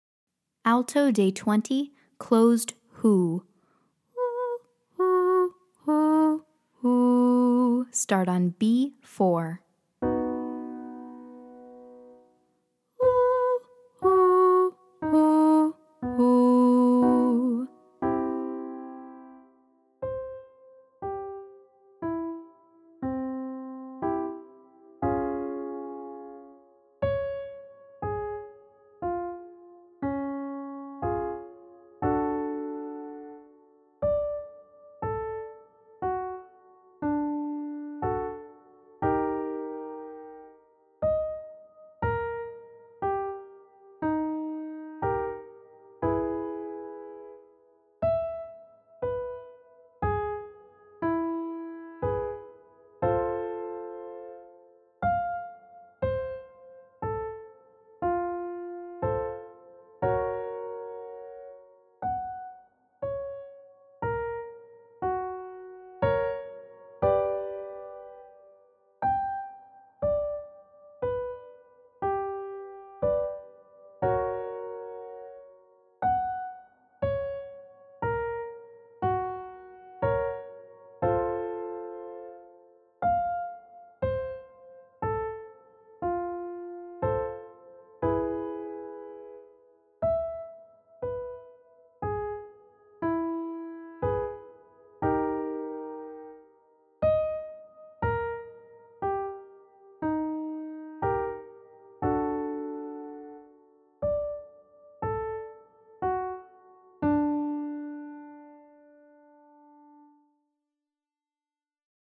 Light doesn’t have to mean breathy.
Day 20 - Alto - Closed 'HOO'